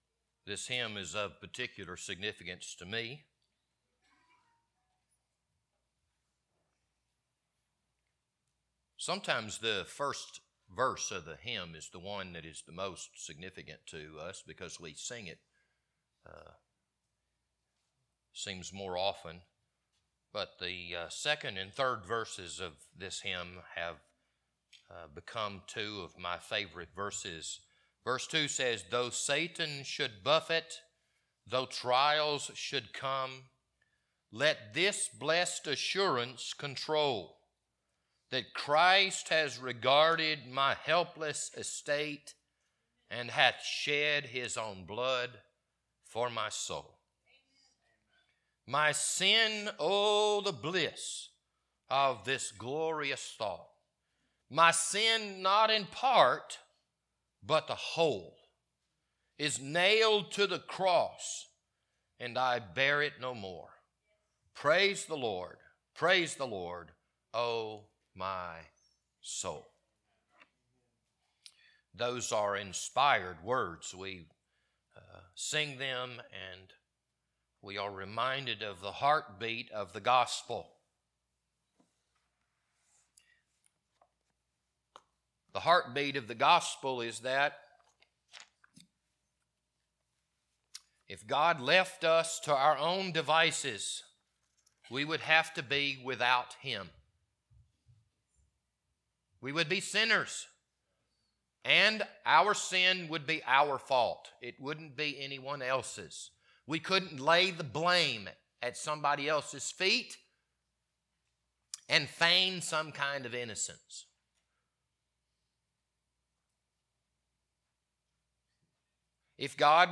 This Sunday morning sermon was recorded on August 6th, 2023.